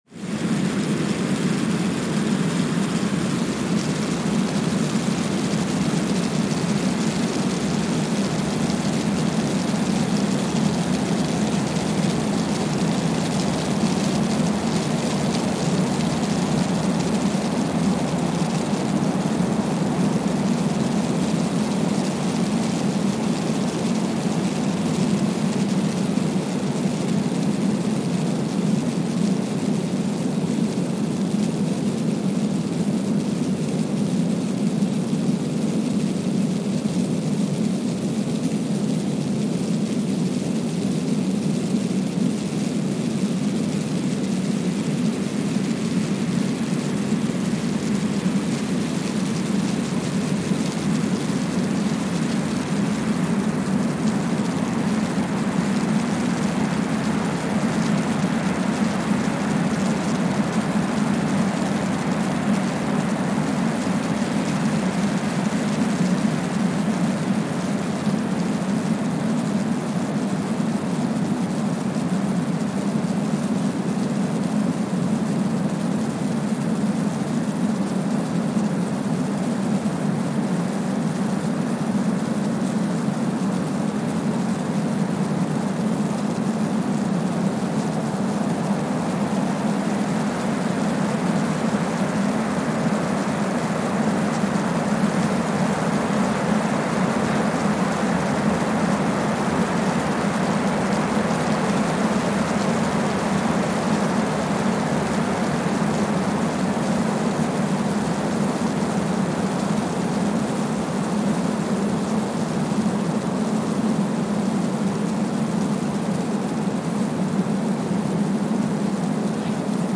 Kwajalein Atoll, Marshall Islands (seismic) archived on November 17, 2020
Sensor : Streckeisen STS-5A Seismometer
Speedup : ×1,000 (transposed up about 10 octaves)
Loop duration (audio) : 05:45 (stereo)
Gain correction : 25dB